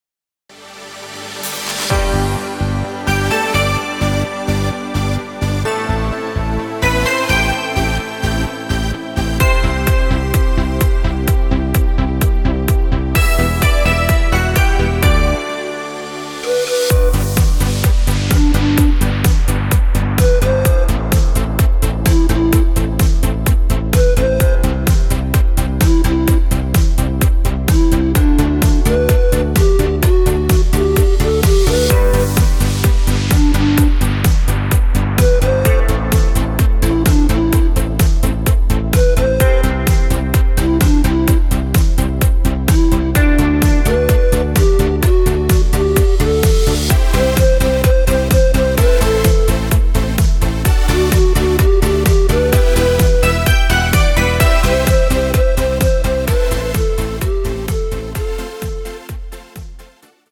Audio Recordings based on Midi-files
Our Suggestions, Pop, German, 2020s